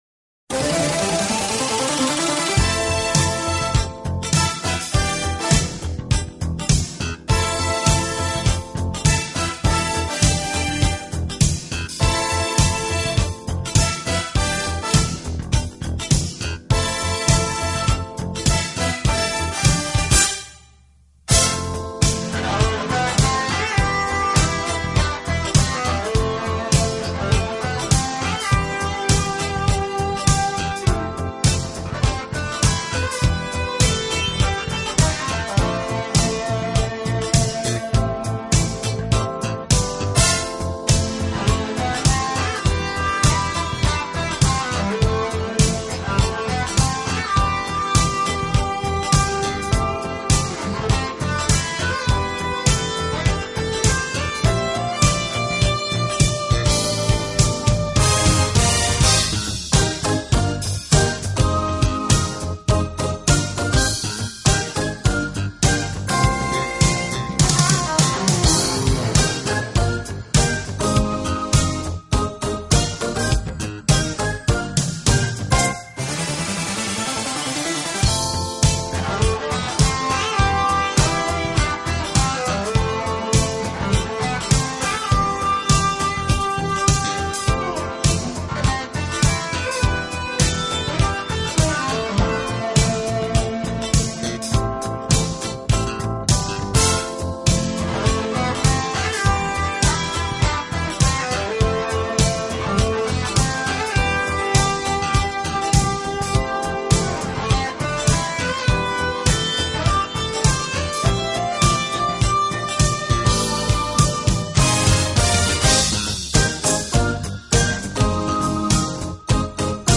一位非著名的日本著名新世紀音樂制作人，Fusion吉他手。
80年代的な快感音楽。